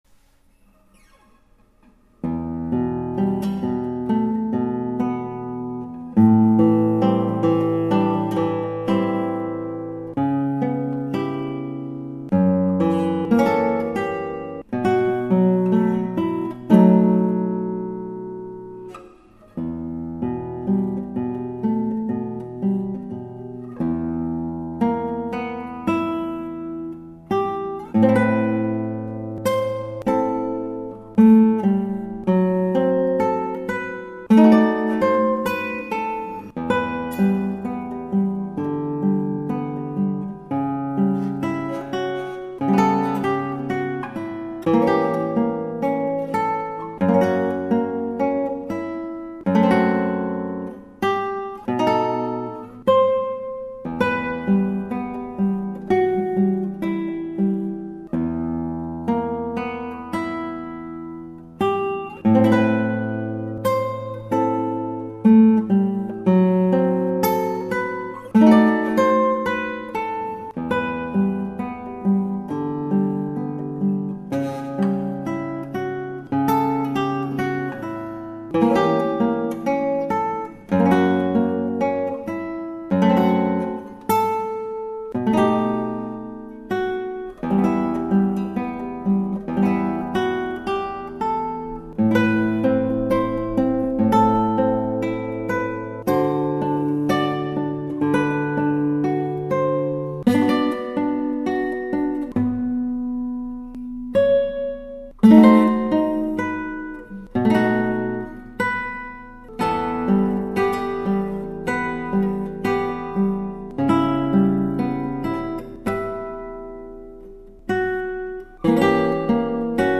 以上ギターはアルカンヘル